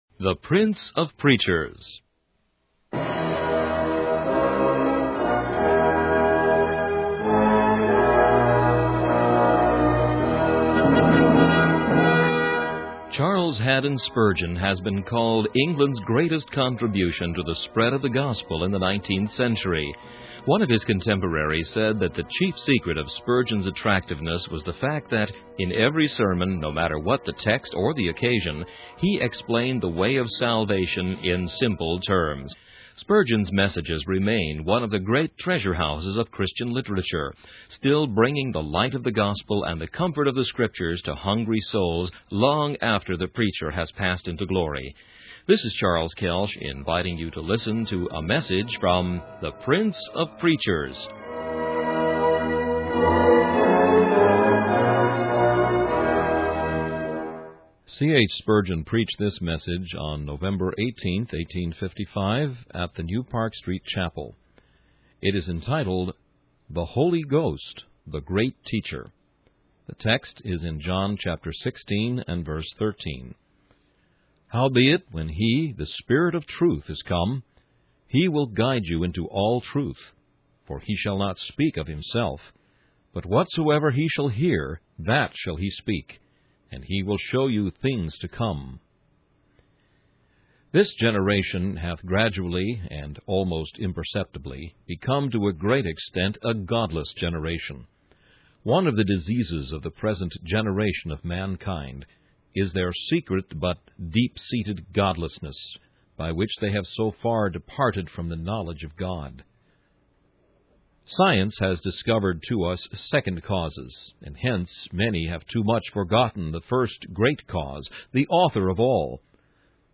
In this sermon, the preacher discusses the role of the Holy Spirit in guiding believers into all truth.